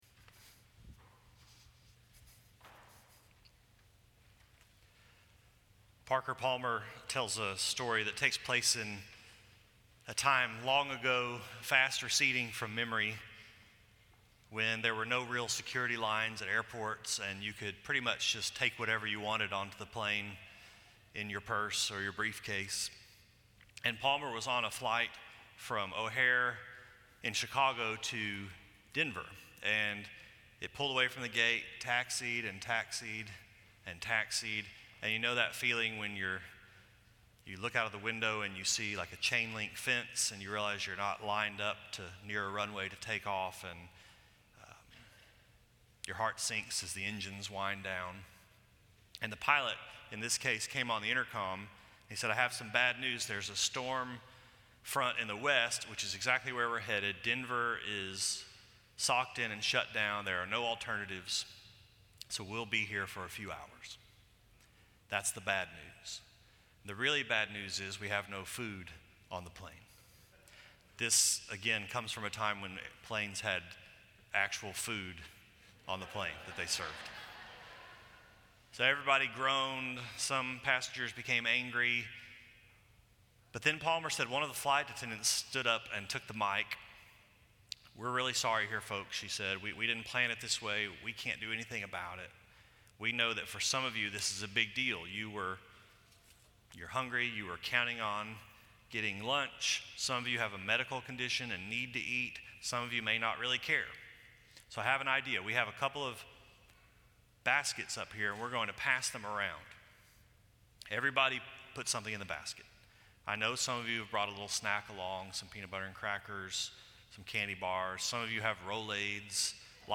This sermon was preached by on September 24, 2017 at Cliff Temple Baptist Church in Dallas, Texas.